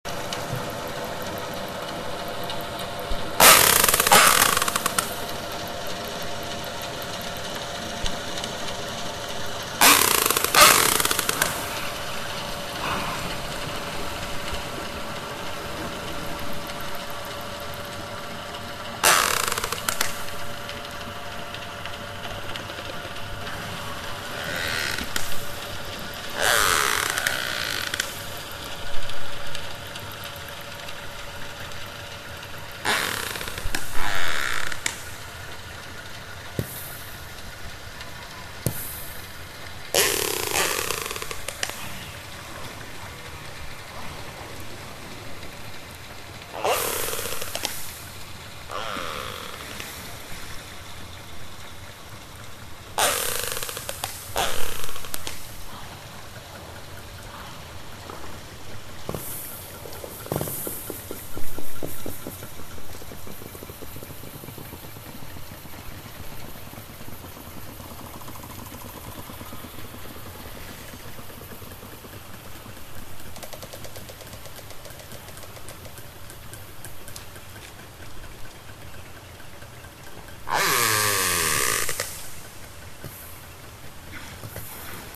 Звуки касаток
Такой звук издает косатка